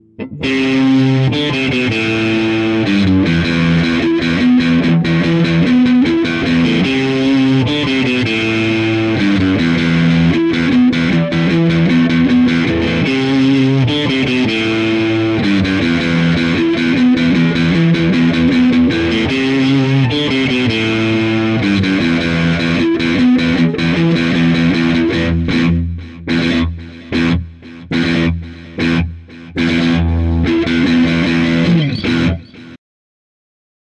描述：电吉他，中型增益，重型但有点干净，有点乡村摇滚，有点国家类型的舔
Tag: 50年代 清洁 乡村摇滚 复古 超速 吉他 扭曲 现代 乡村 电子